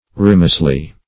rimosely - definition of rimosely - synonyms, pronunciation, spelling from Free Dictionary Search Result for " rimosely" : The Collaborative International Dictionary of English v.0.48: Rimosely \Ri*mose"ly\, adv.